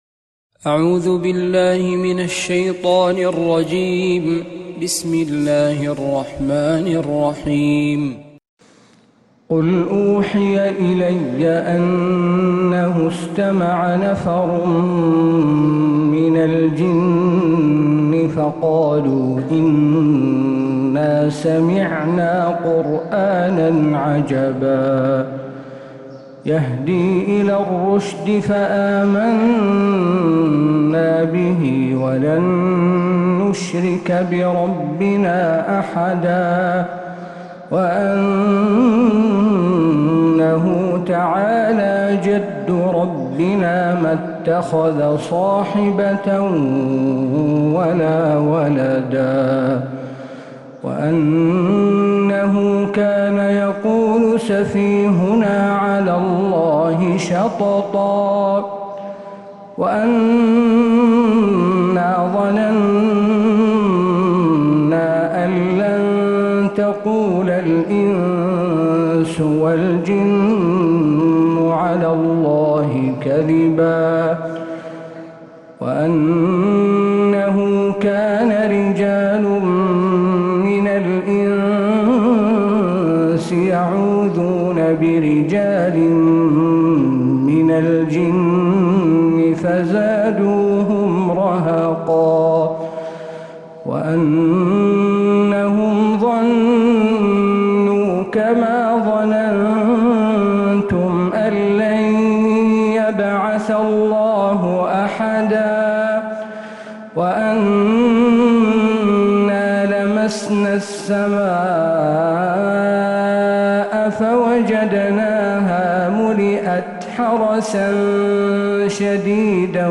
سورة الجن كاملة من فجريات الحرم النبوي